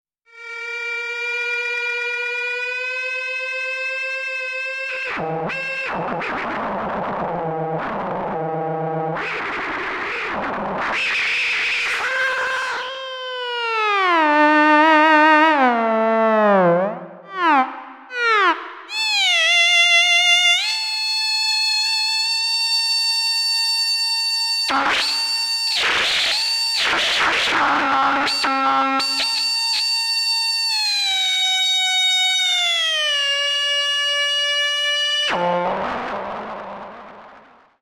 No other effects processing was used other than some normalization, and each example is a single track. All effects and pitch modulation were improvised using the FLUX bows.